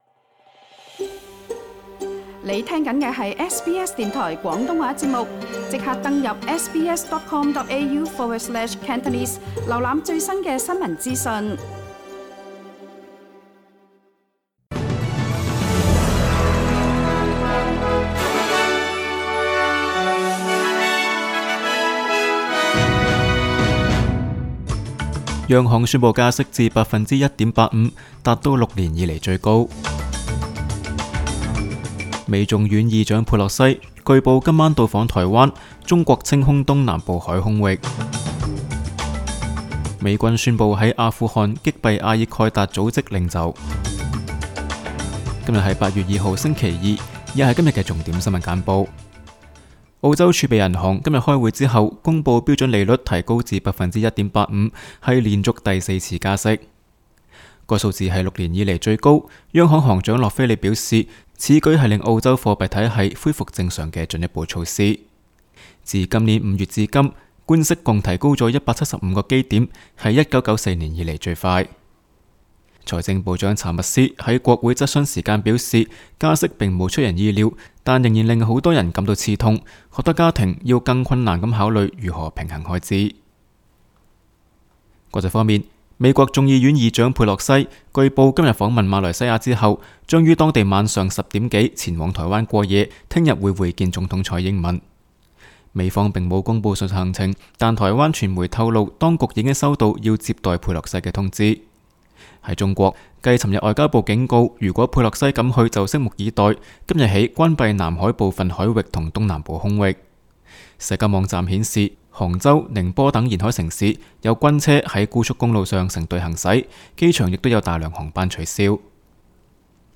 SBS 新闻简报（8月2日）
SBS 廣東話節目新聞簡報 Source: SBS Cantonese